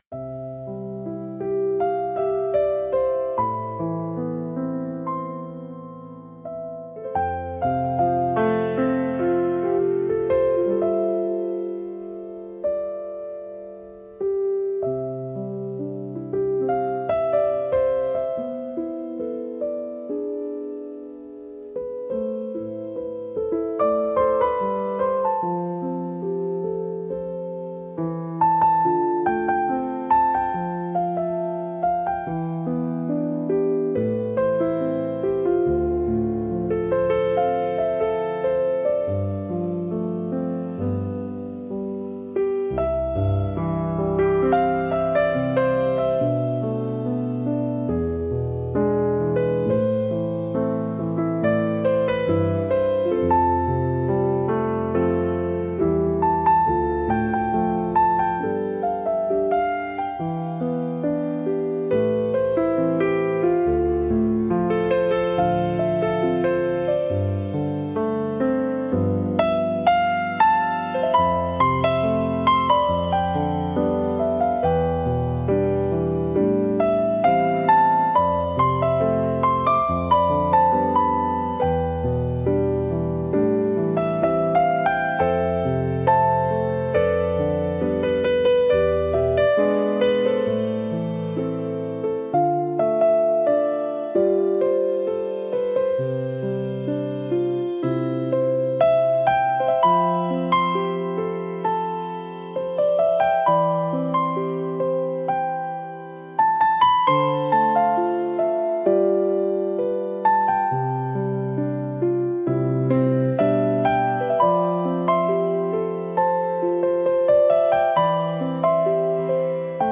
繊細で情感豊かな空気を醸し出すジャンルです。